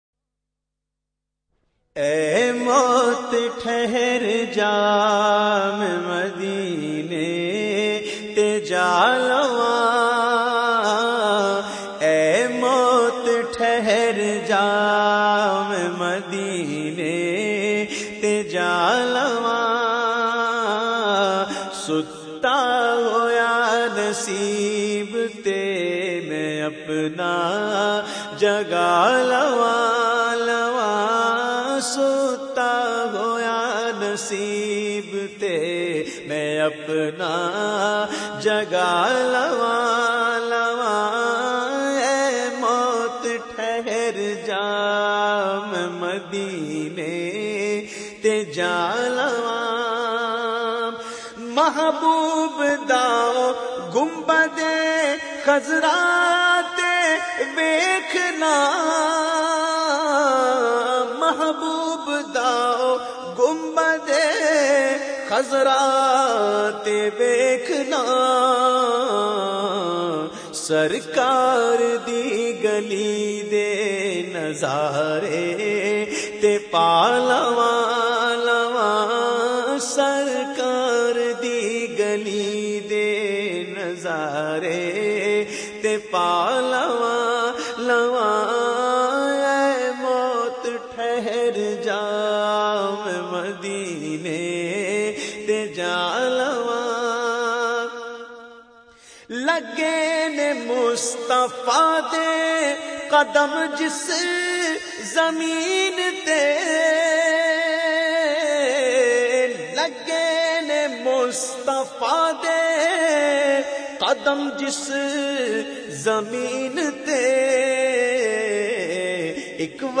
The Naat Sharif Aye Mot Theher Ja recited by famous Naat Khawan of Pakistan Owais Raza Qadri.